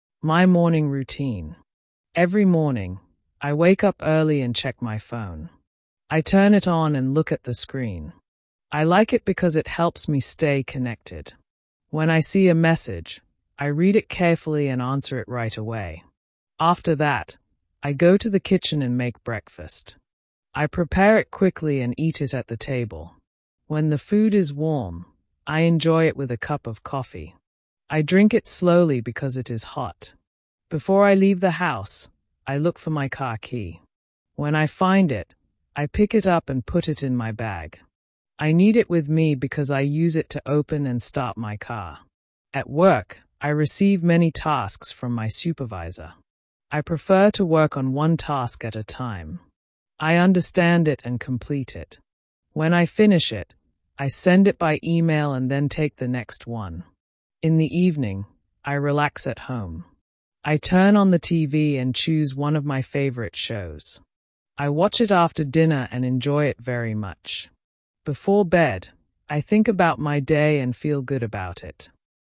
Pronouncing “it” after verbs - Practice